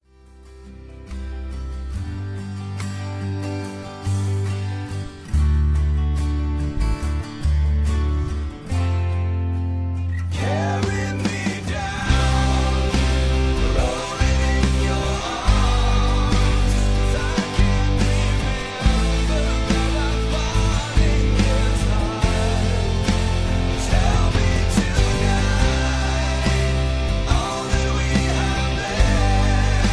(Key-C) Karaoke MP3 Backing Tracks
mp3 backing tracks